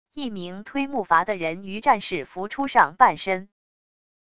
一名推木筏的人鱼战士浮出上半身wav音频